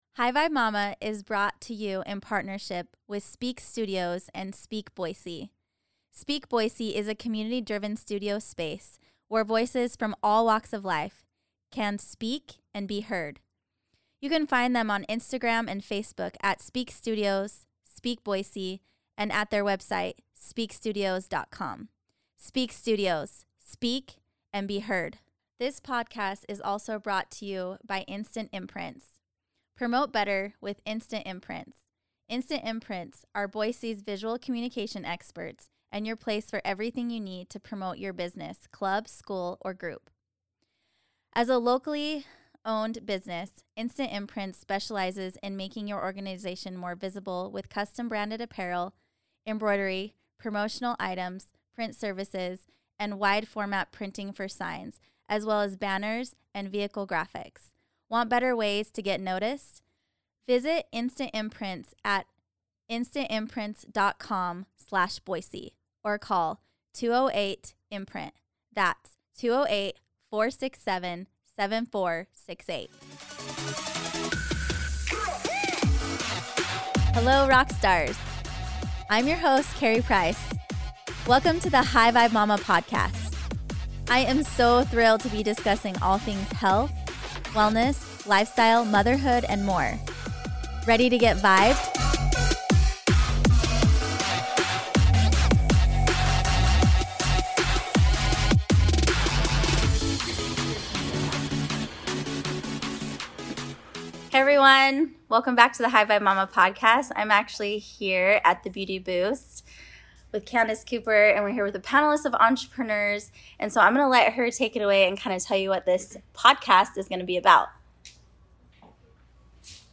We captured the moments and stories for you, so listen in on all these powerful women and connect with them!!